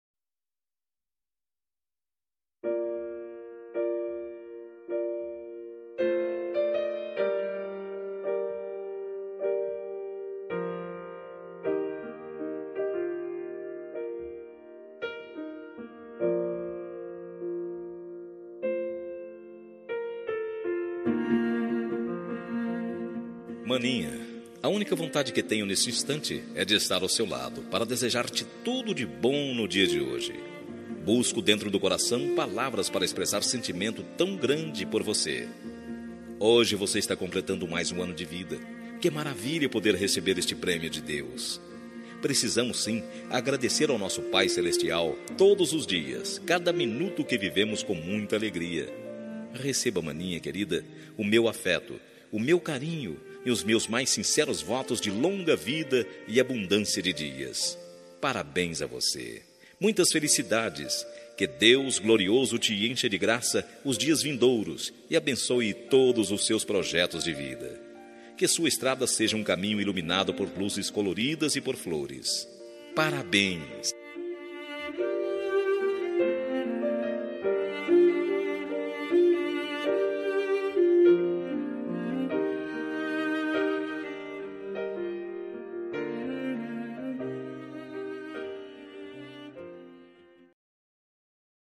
Telemensagem de Aniversário de Irmã – Voz Masculina – Cód: 4022